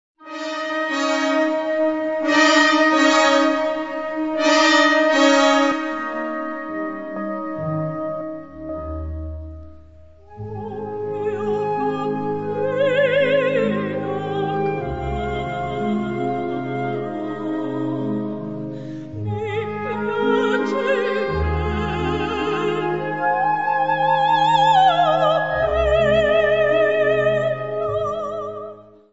Gattung: Sologesang (Sopran) und Blasorchester
Besetzung: Blasorchester